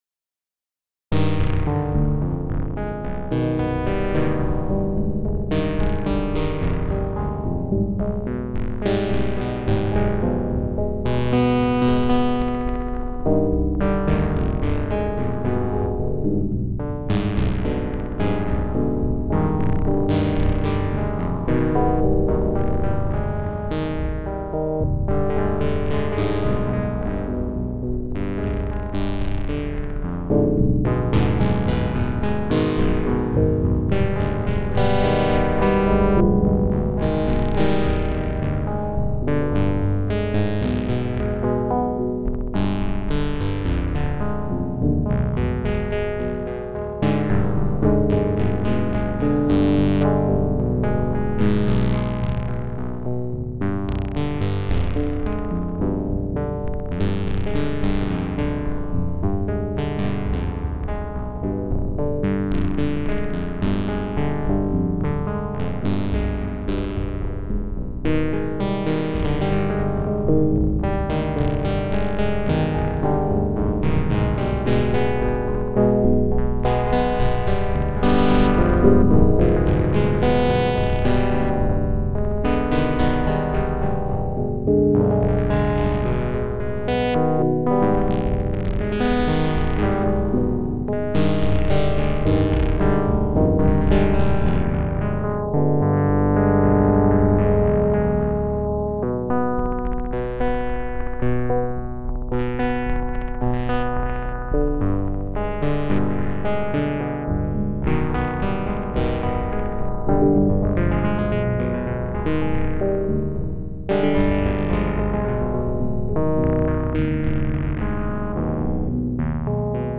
Solo Improvisations